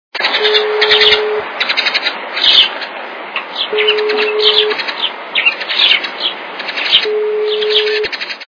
» Звуки » Природа животные » Звук - Воробей
При прослушивании Звук - Воробей качество понижено и присутствуют гудки.
Звук Звук - Воробей